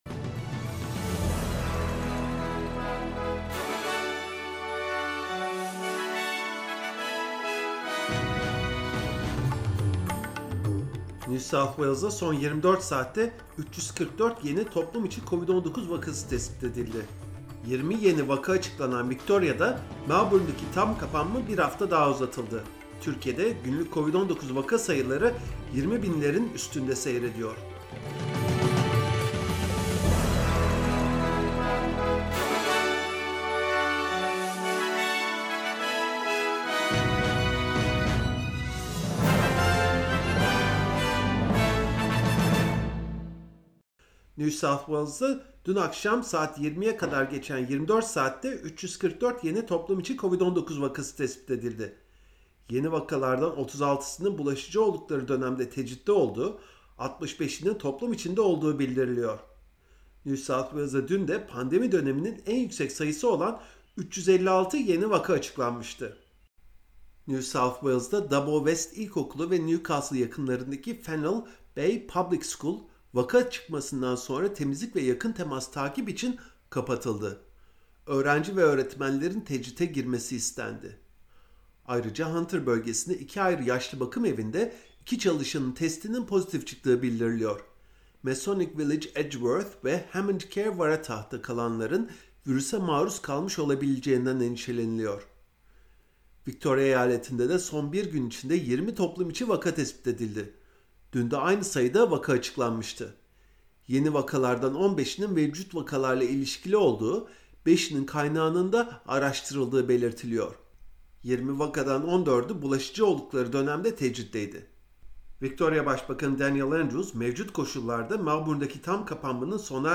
SBS Türkçe Haberler 11 Ağustos